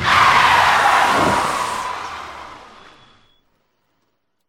skid3.ogg